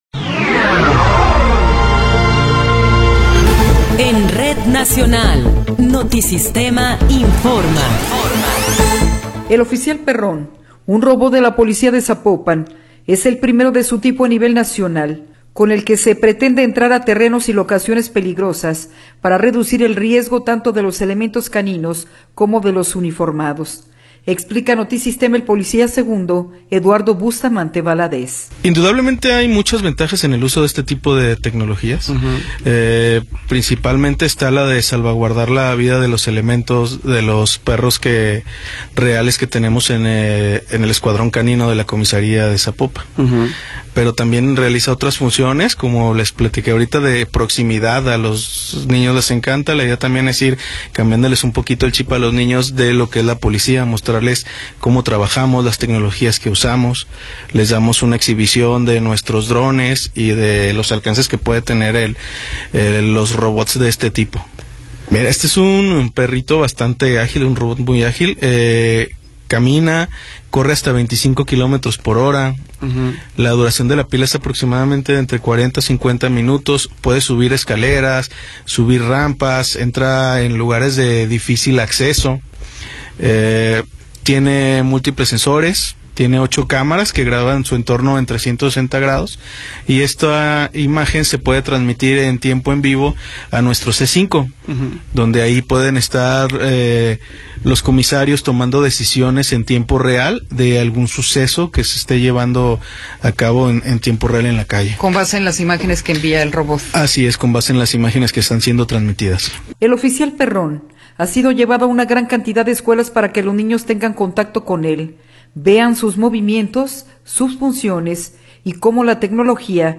Noticiero 21 hrs. – 18 de Febrero de 2024